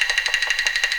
A#3 STICK0BR.wav